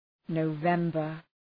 Προφορά
{nəʋ’vembər}